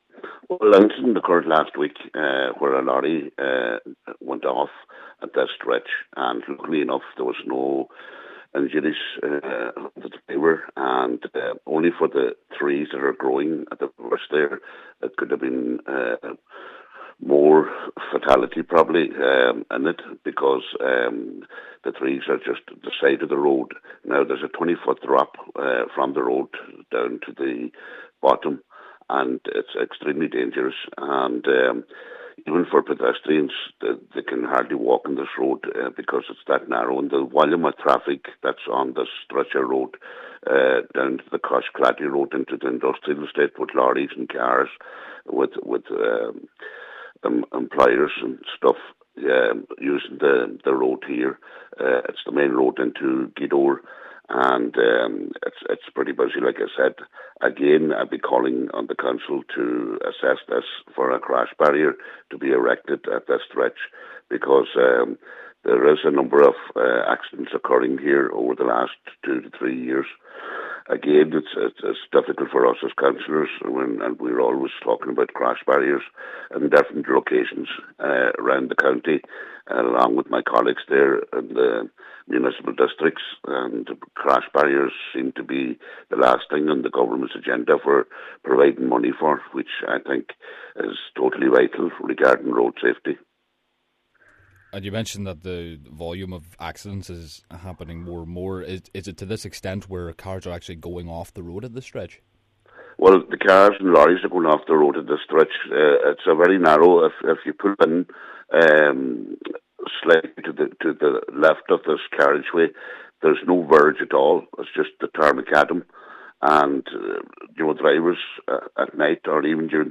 Cllr John Shéamais Ó Fearraigh says that if trees were not growing off the stretch of road there could have been a fatality: